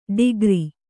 ♪ ḍigri